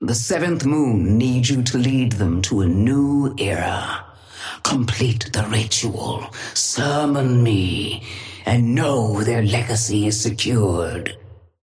Sapphire Flame voice line - The Seventh Moon needs you to lead them to a new era.
Patron_female_ally_yamato_start_02.mp3